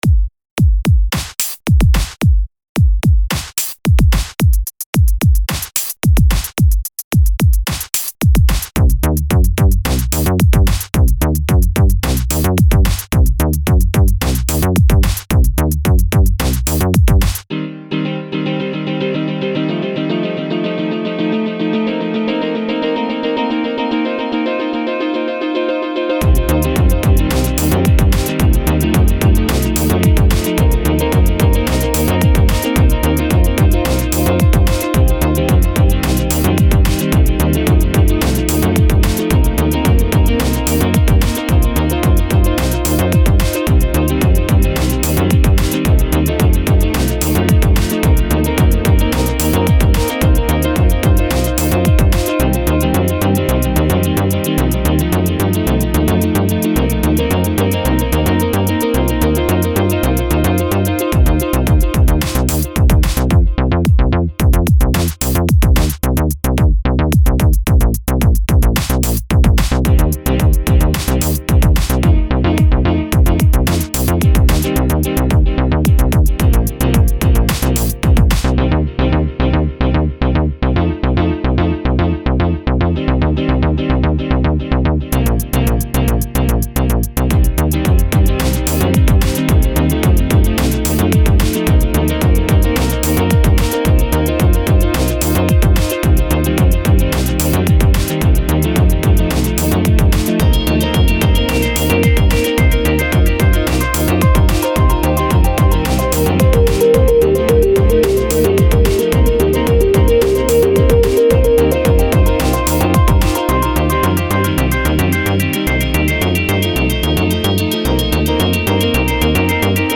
Neutral dark-lounge sounding track.
• Music is loop-able, but also has an ending